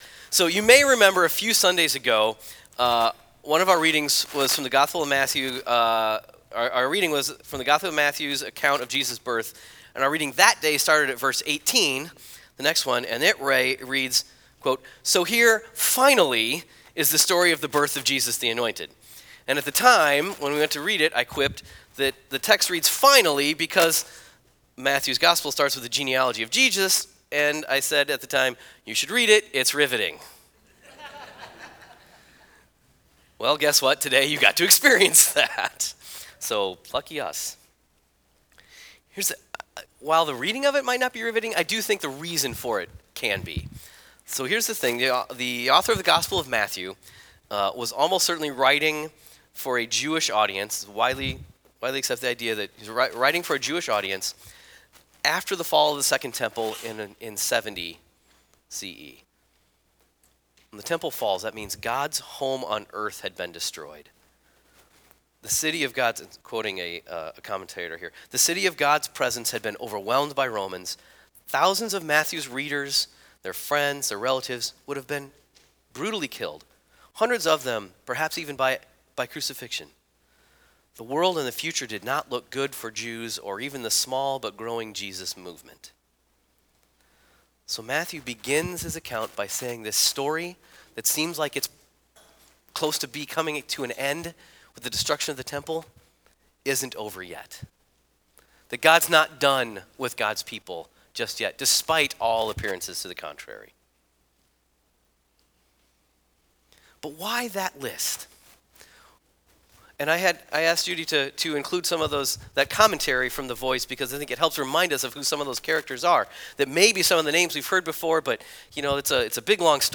*Please forgive the nasally quality of my voice in this recording. I was fighting a bad cold that day.